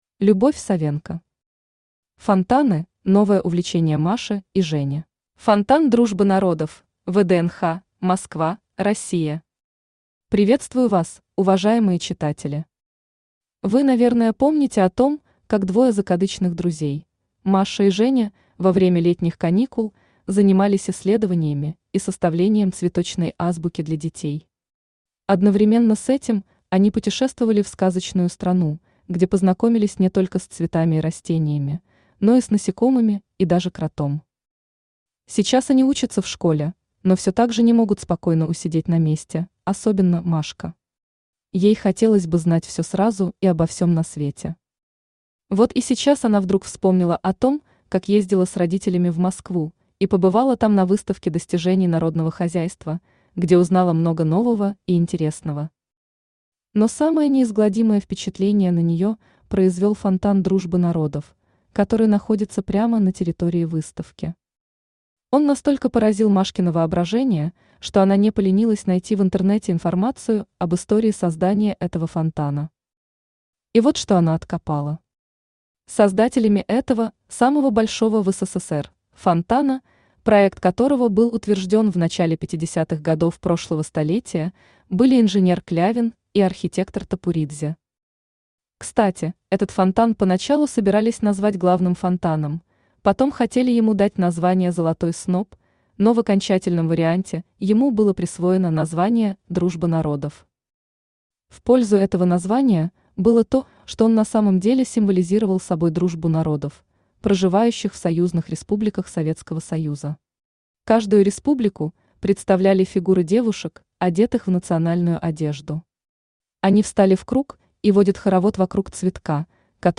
Aудиокнига Фонтаны – новое увлечение Маши и Жени Автор Любовь Савенко Читает аудиокнигу Авточтец ЛитРес.